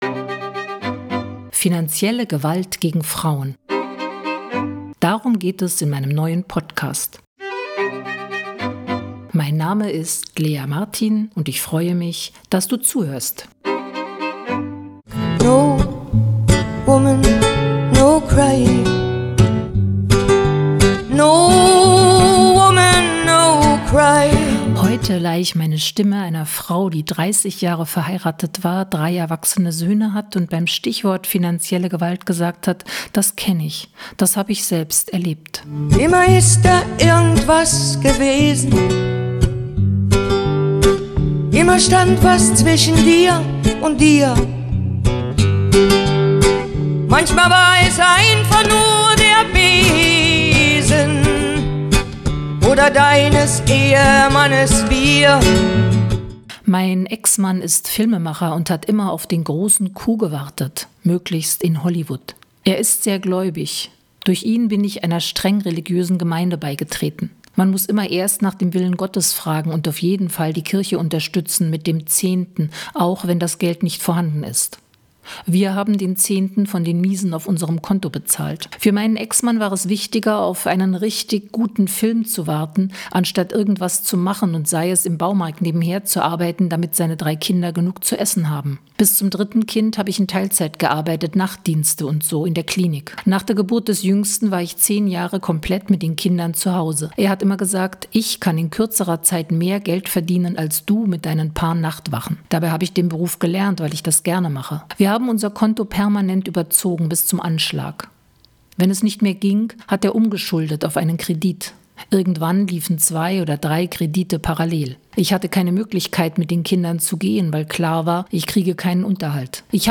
finanzieller Gewalt« leihe ich einer Frau meine Stimme, der es